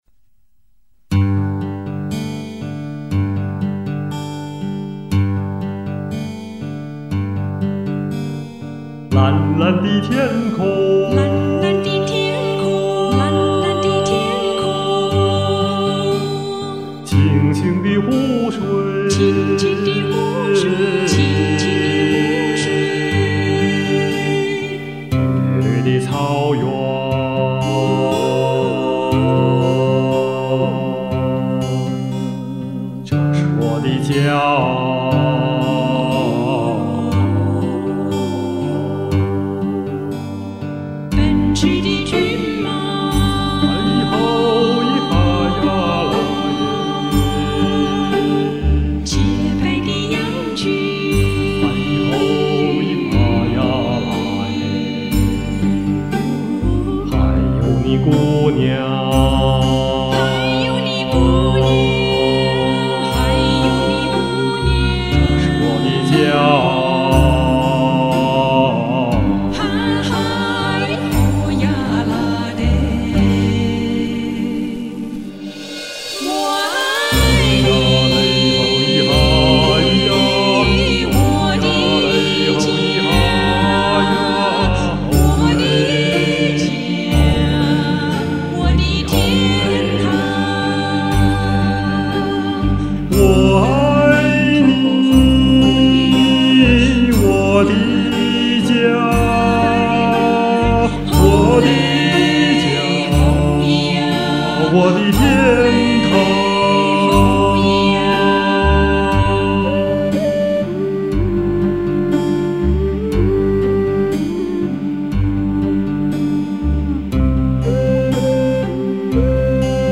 很有特色的重唱演绎！
美妙的和声，美丽的贴子，享受啊！
俺喜爱的一首歌!又创一种演绎,舒展悠远的感觉,很美!